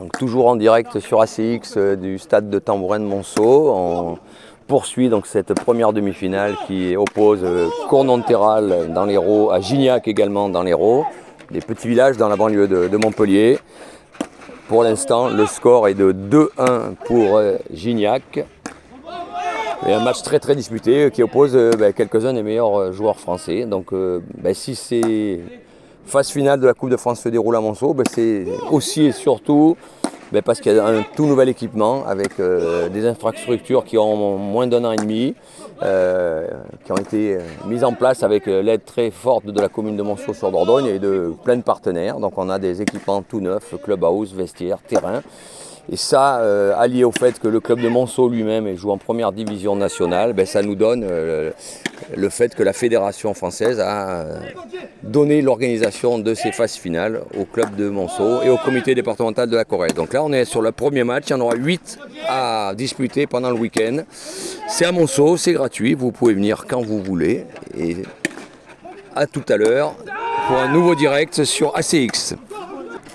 tambourins4.mp3